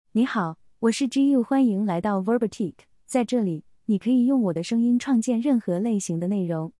ZhiyuFemale Chinese Mandarin AI voice
Zhiyu is a female AI voice for Chinese Mandarin.
Voice sample
Listen to Zhiyu's female Chinese Mandarin voice.
Female
Zhiyu delivers clear pronunciation with authentic Chinese Mandarin intonation, making your content sound professionally produced.